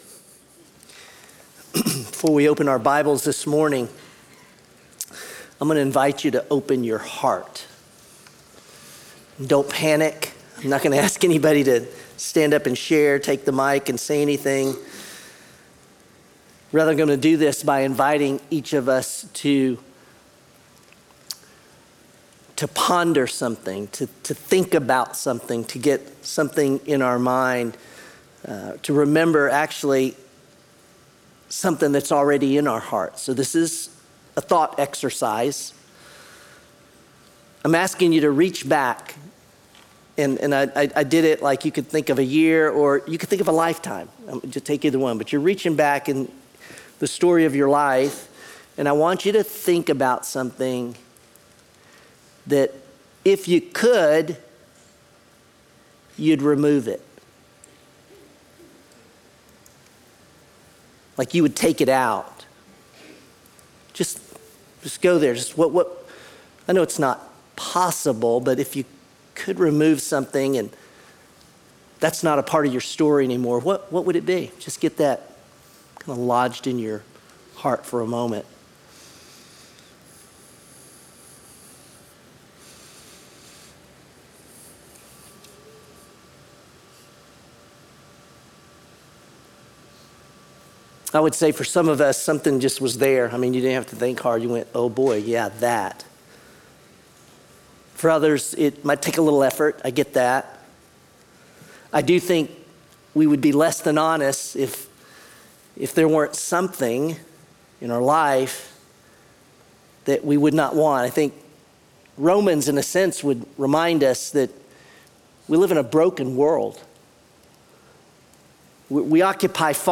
Sermon Unshakeable: Romans 8